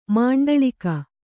♪ māṇḍaḷika